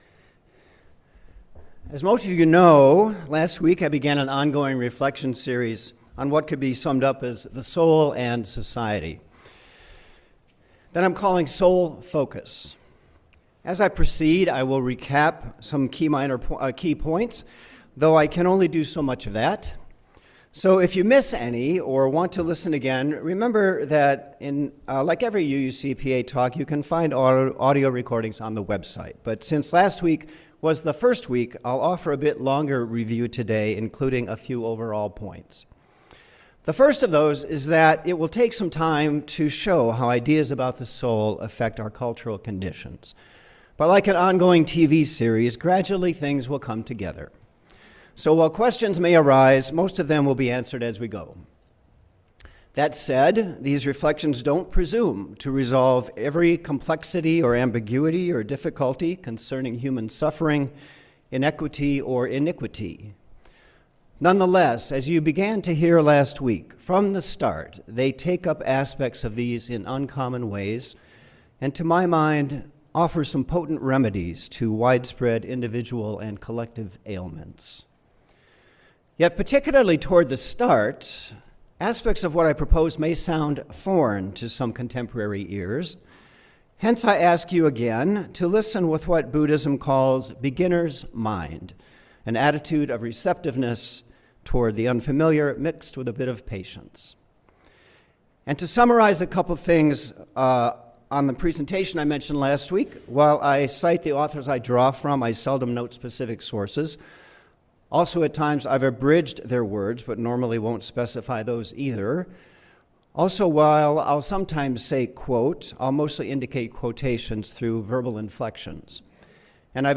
Unitarian Universalist Church of Palo Alto Sermons and Reflections – Unitarian Universalist Church of Palo Alto